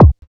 EQ KICK 7 1.wav